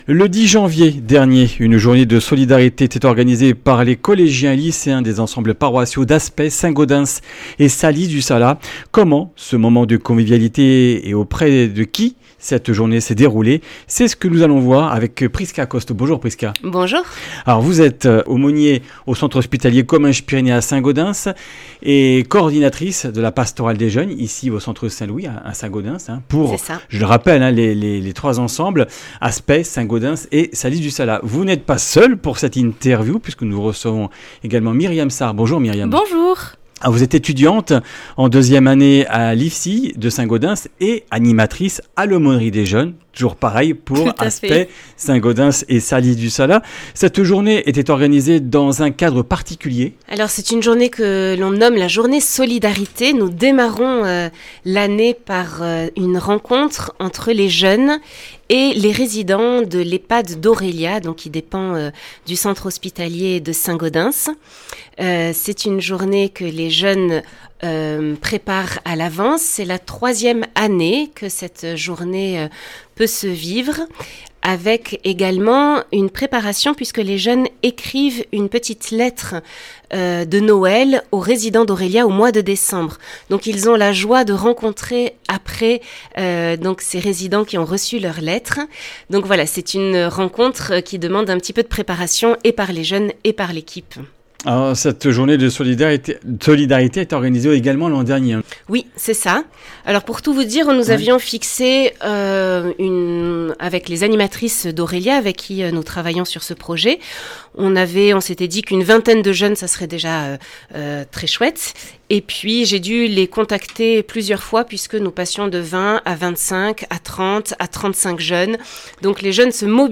Comminges Interviews du 26 janv.